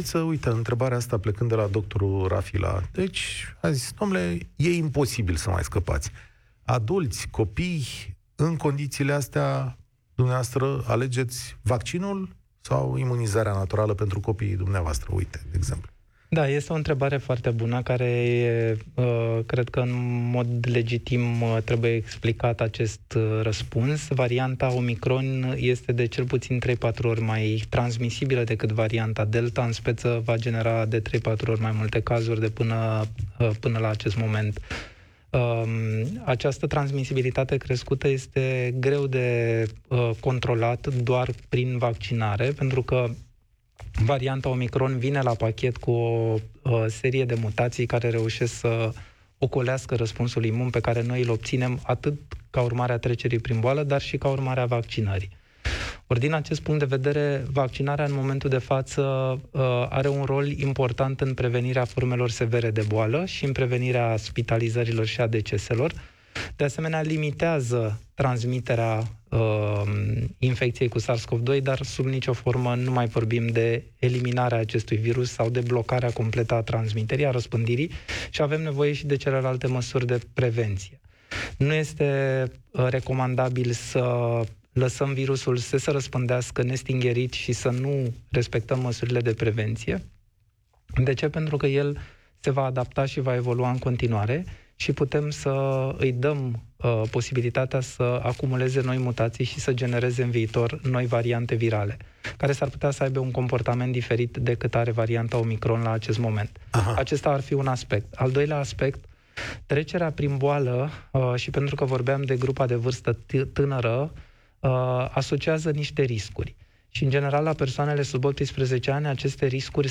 Valeriu Gheorghiță, coordonatorul campaniei de vaccinare anti-COVID în România, a fost invitatul lui Cătălin Striblea în emisiunea „România în Direct” de la Europa FM.